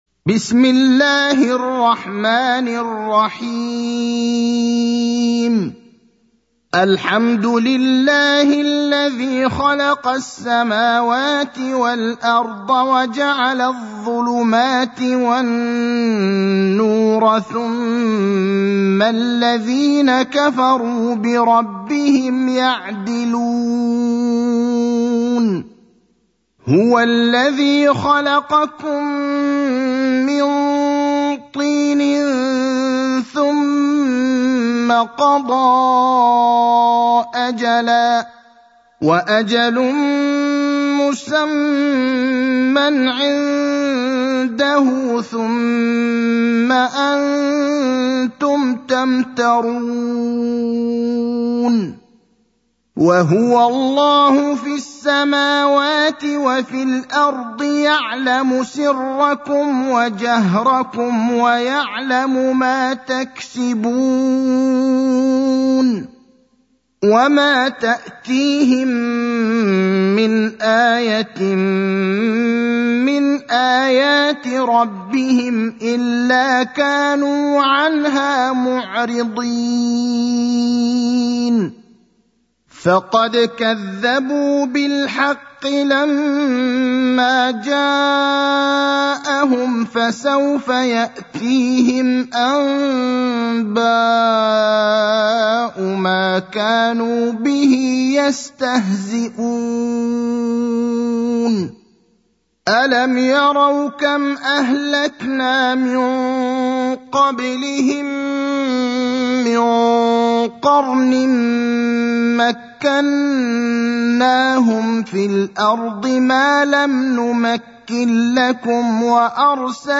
المكان: المسجد النبوي الشيخ: فضيلة الشيخ إبراهيم الأخضر فضيلة الشيخ إبراهيم الأخضر الأنعام (6)